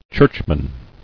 [church·man]